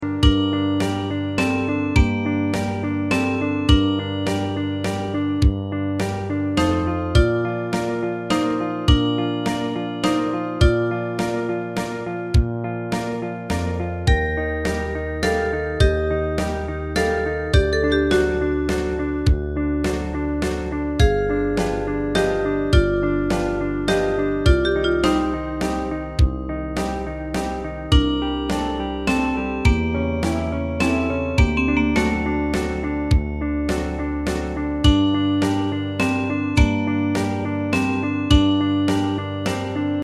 大正琴の「楽譜、練習用の音」データのセットをダウンロードで『すぐに』お届け！
Ensemble musical score and practice for data.